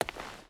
Footsteps / Stone
Stone Walk 2.wav